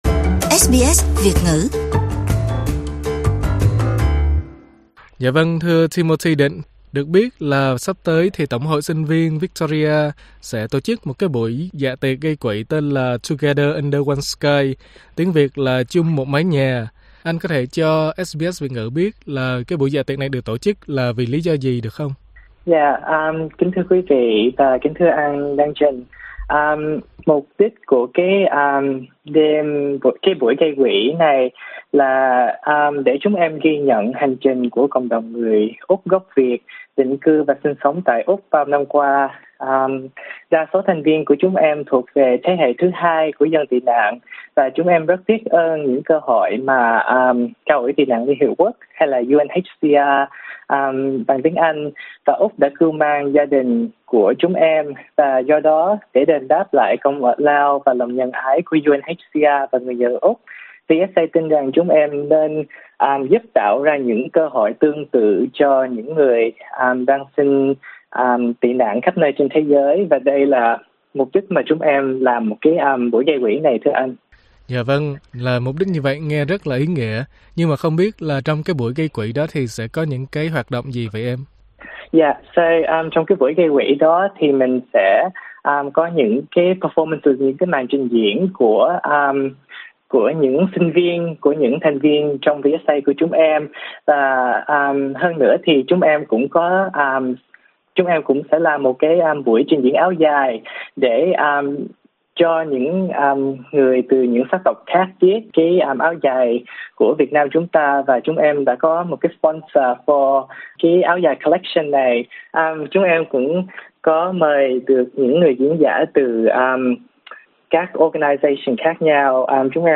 Mời quý vị bấm vào phần audio để nghe cuộc phỏng vấn của SBS Vietnamese